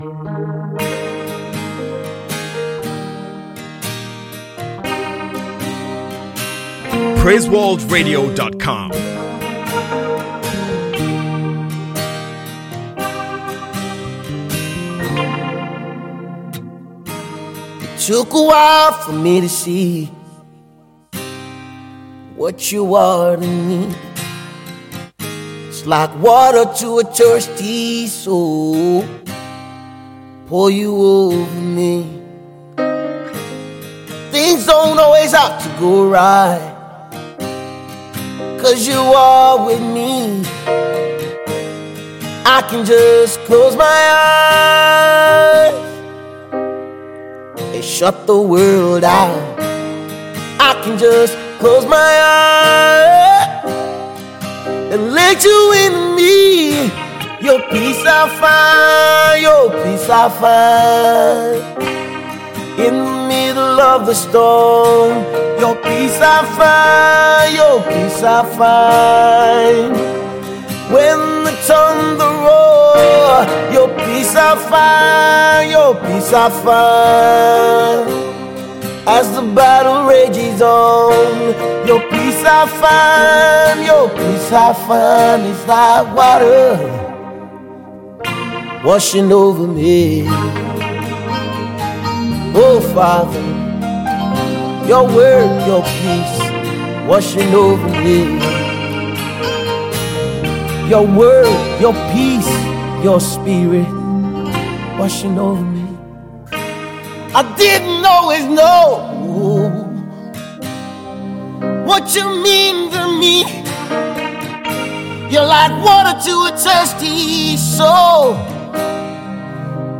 classic gospel country tune